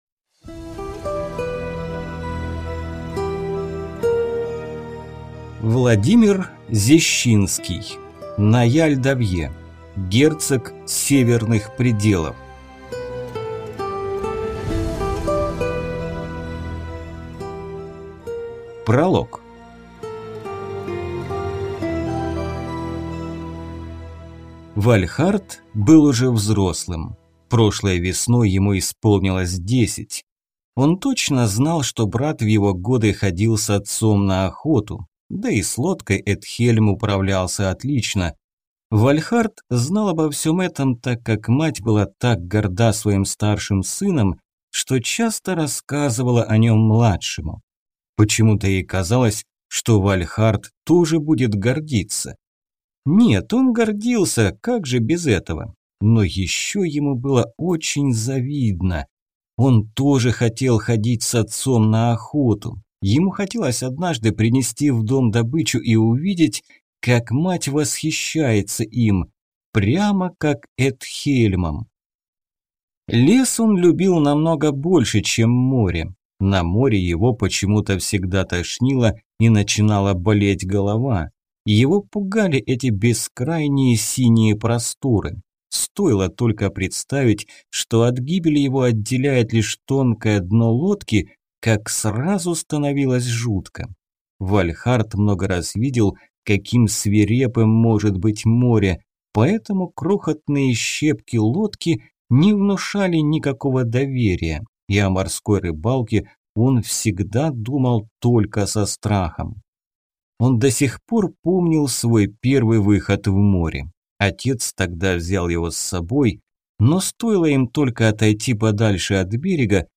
Аудиокнига Наяль Давье. Герцог северных пределов | Библиотека аудиокниг